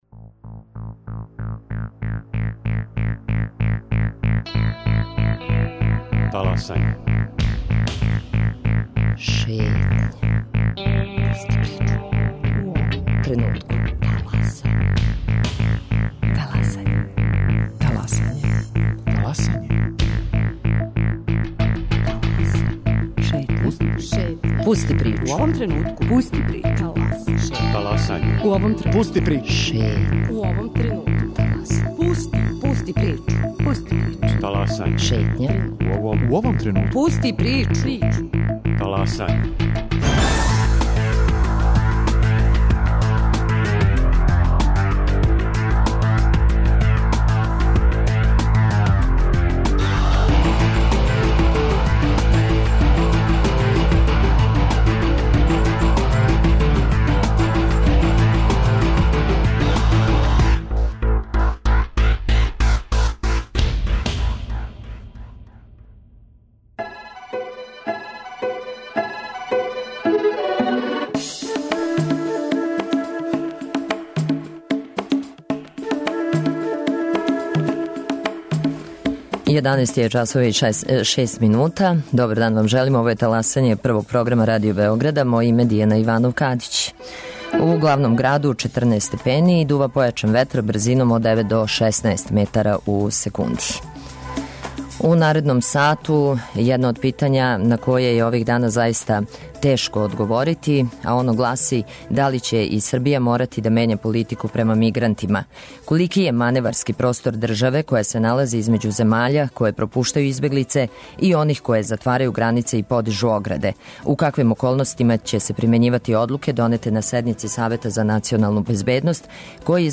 Због чега нова правила не решавају старе проблеме избегличке кризе, за Таласање говоре Владимир Цуцић, комесар за избеглице и члан Радне групе за решавање проблема мешовитих миграционих токова и Ненад Иванишевић, државни секретар Министарства за рад, запошљавање, борачка и социјална питања. Наши дописници и репортери јавиће се из Грчке, са граничних прелаза и из прихватних центара.